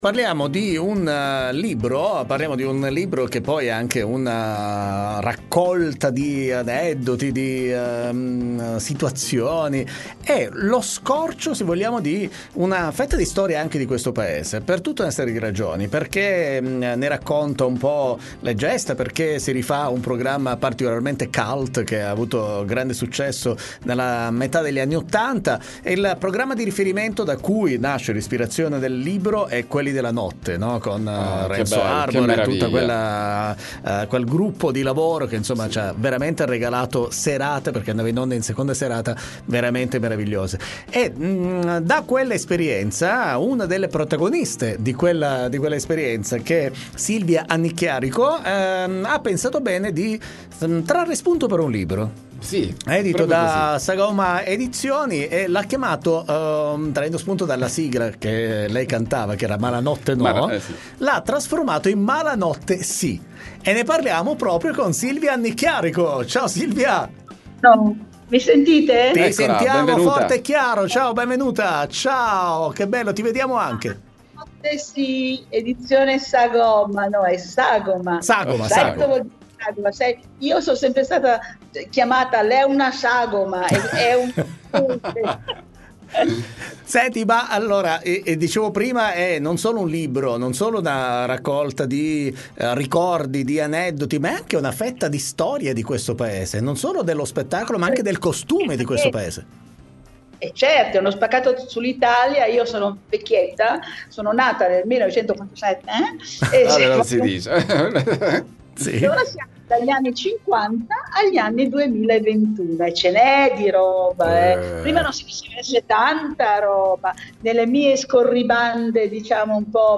Doc Time intervista